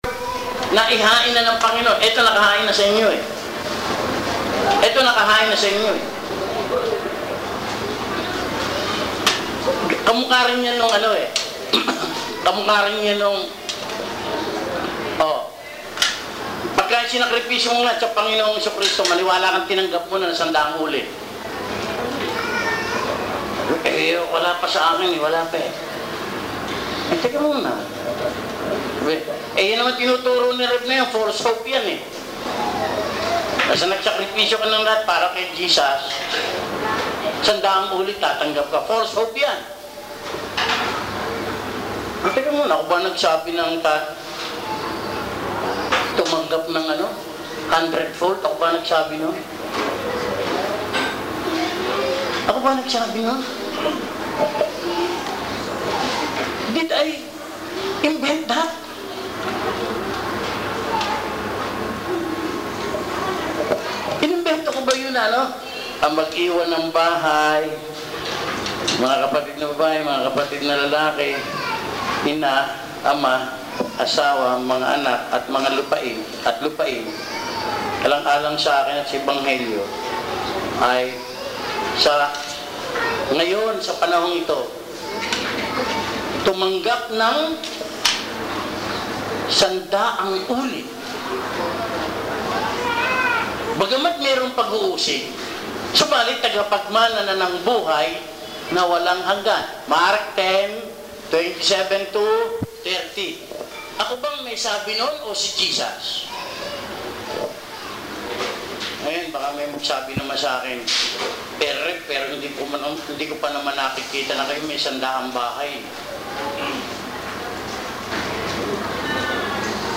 Posted on April 3, 2015 in audio bible study, Bible study, Christianity, faith, God, JESUS CHRIST, lectures, religion, sermons, spirituality